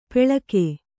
♪ peḷake